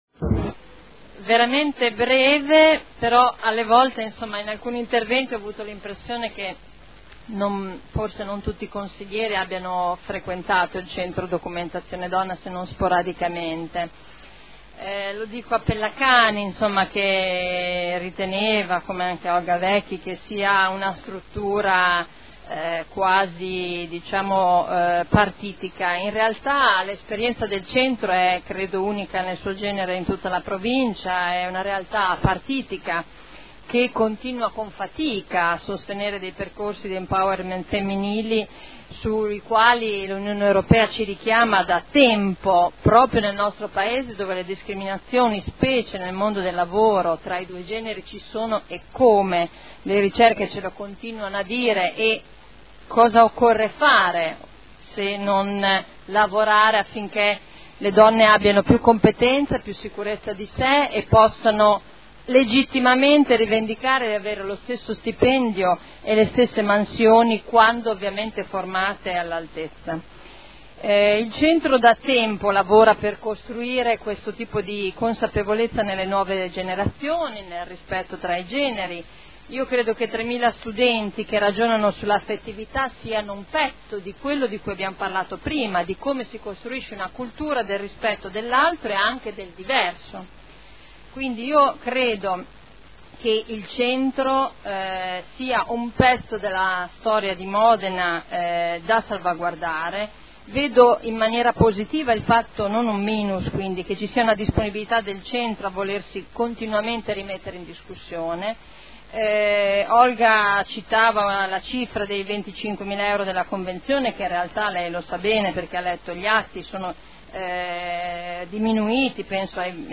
Simona Arletti — Sito Audio Consiglio Comunale